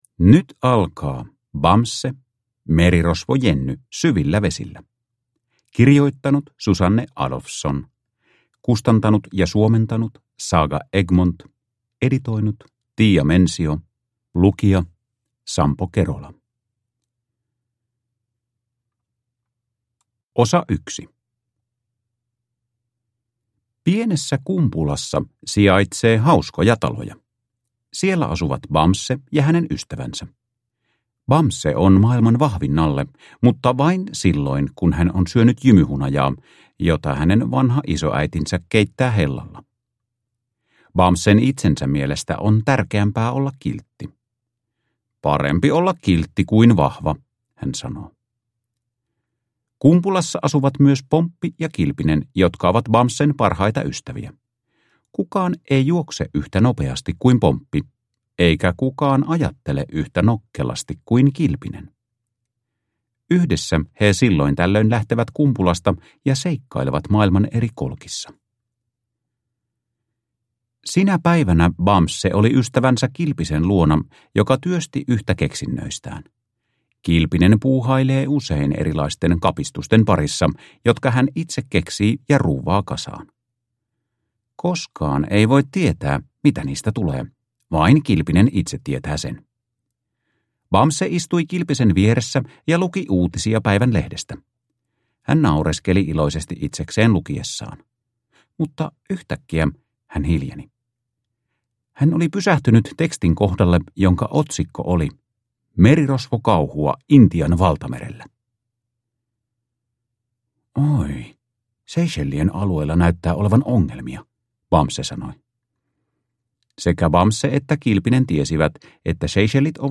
Bamse - Merirosvo Jenny syvillä vesillä – Ljudbok